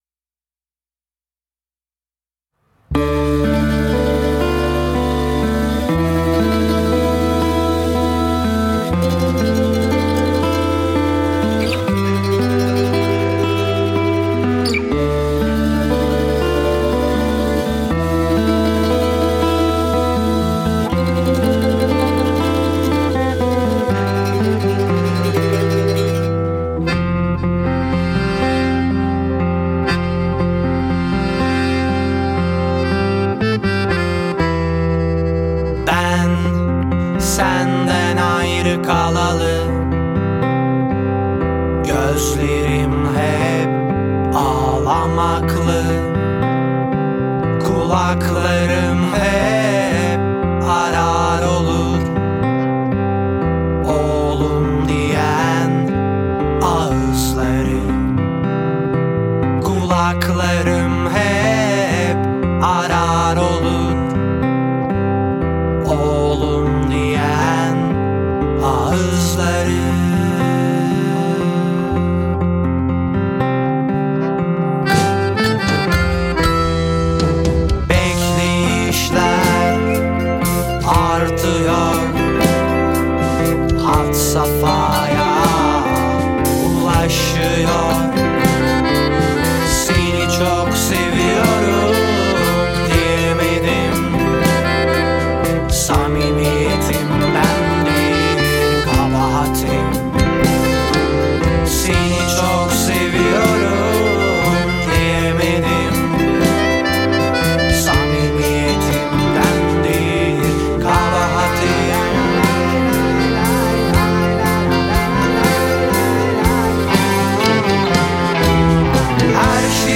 Mixture: Producción de un EP de fusión entre rock progresivo, gaita, flauta turca, acordeón, congas y mandolina